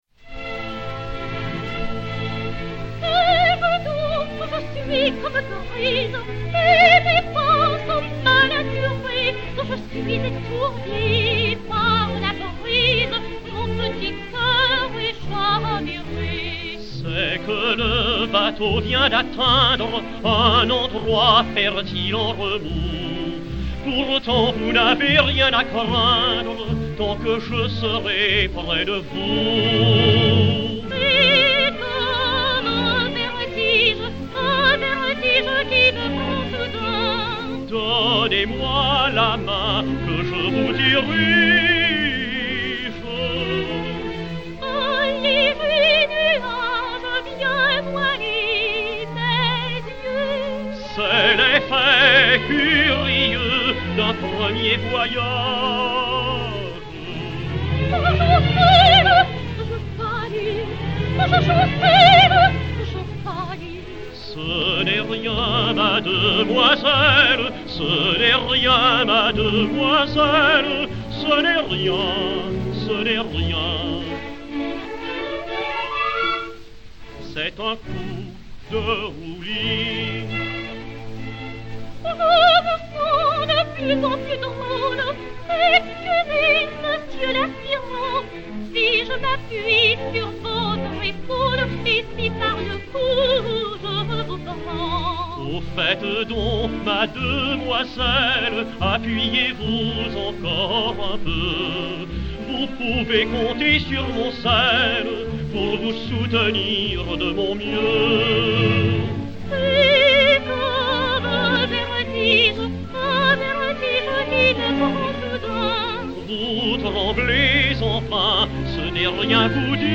et Orchestre dir.